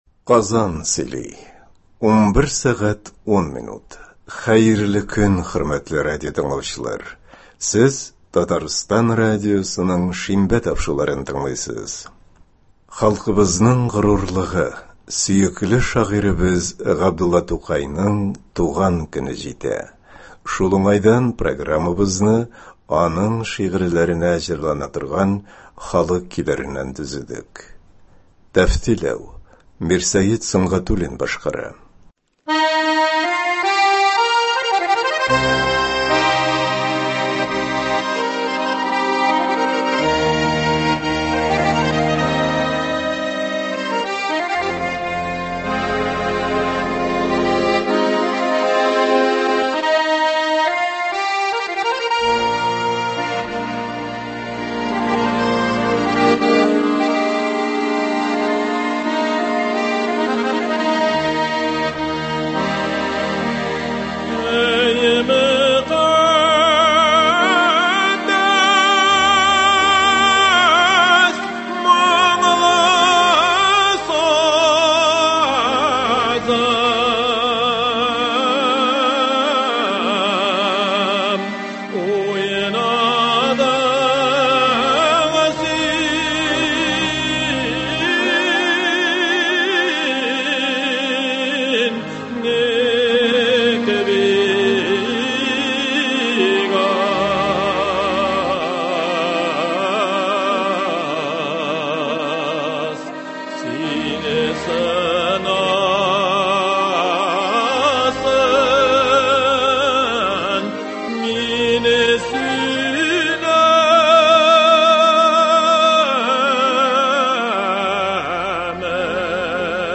Габдулла Тукай шигырьләренә татар халык җырлары.
Концерт (24.04.21)